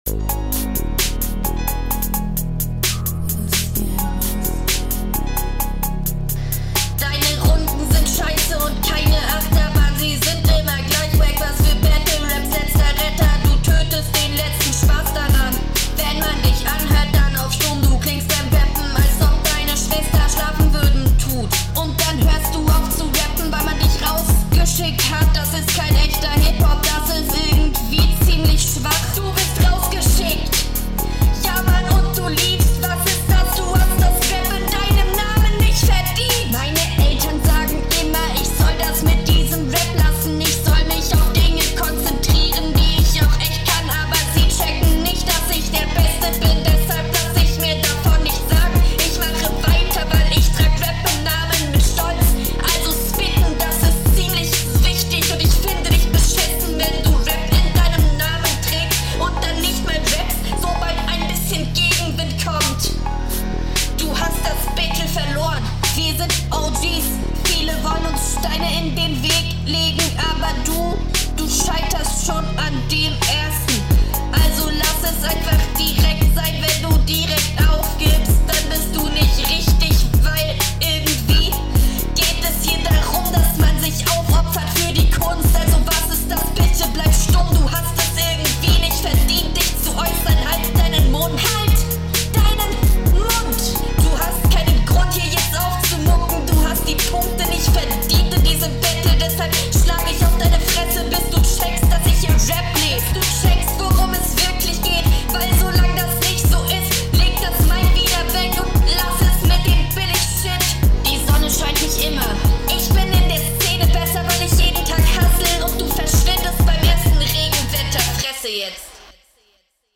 Die Runde war verständlicher als die Erste.